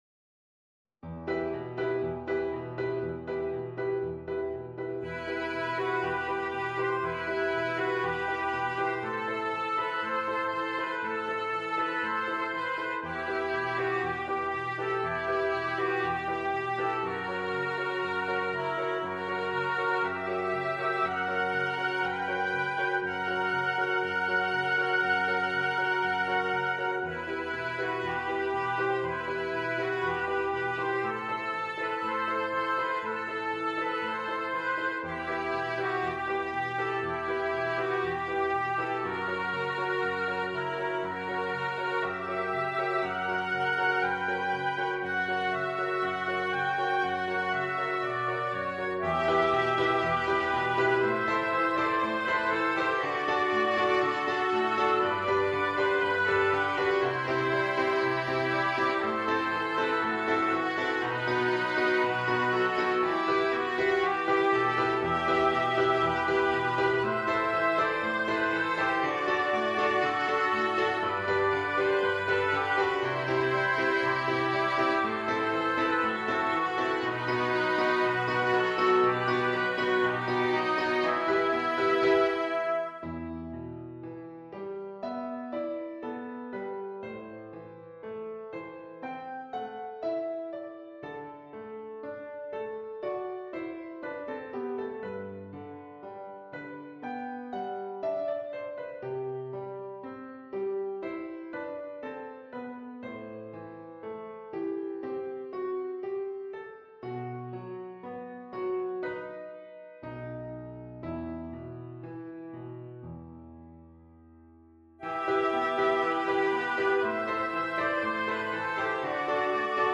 per 2 oboi e pianoforte